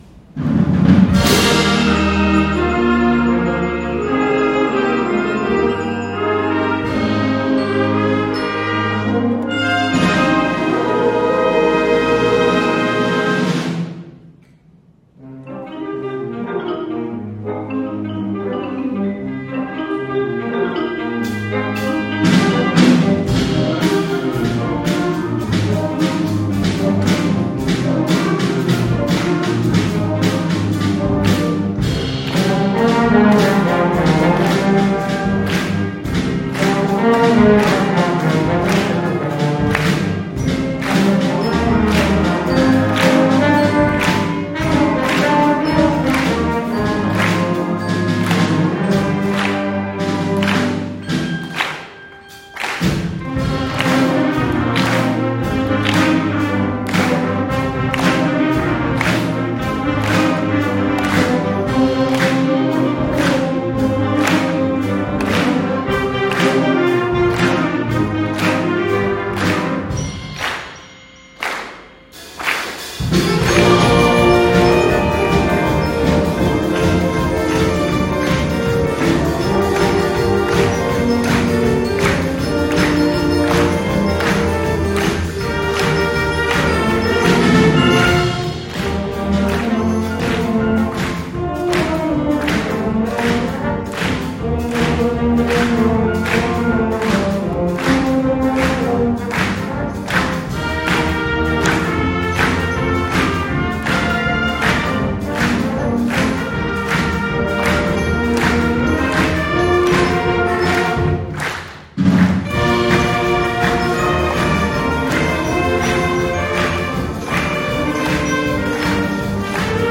第41回文化発表会の舞台発表は、生徒会による活動報告と吹奏楽部の活気ある演奏で幕を開けました。
The stage performances at the 41st Culture Festival kicked off with a report on activities by the student council and a dynamic performance by the brass band.